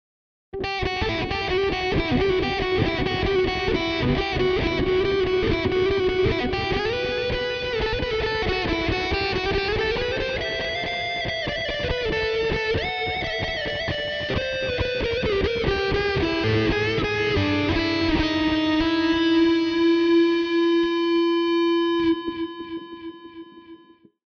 Пример звука Revolver (drive-reverberator)
Записано на гитаре Fender Squier
drive-rever.mp3